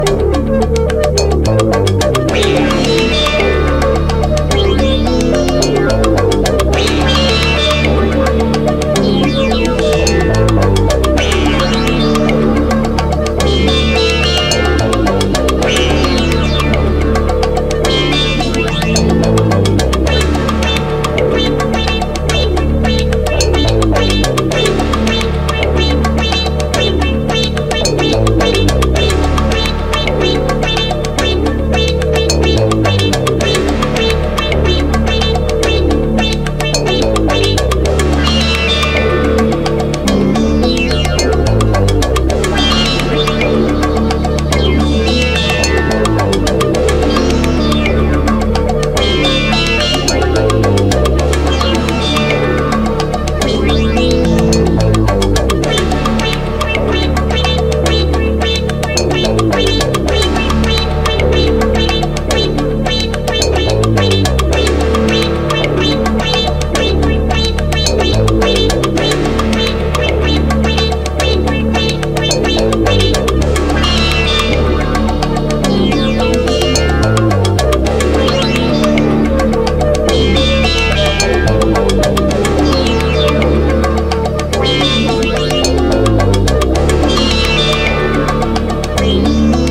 BREAKBEATS/HOUSE / TRIP HOP / DOWNTEMPO